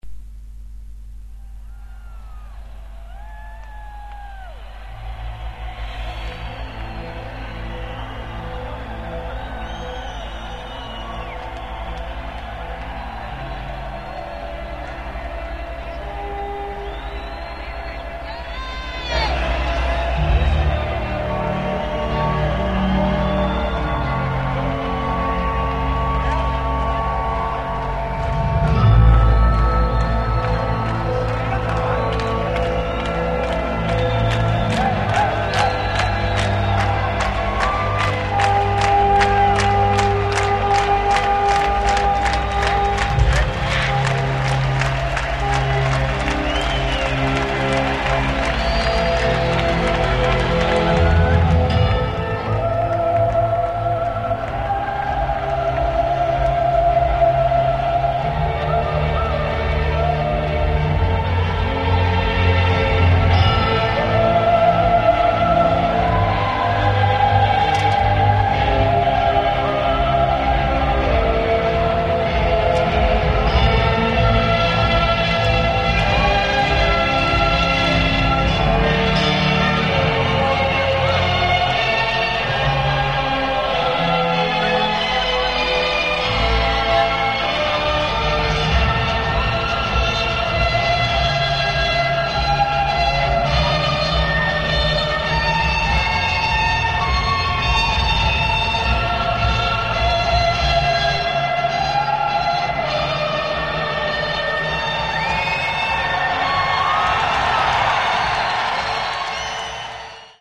(Concert Intro)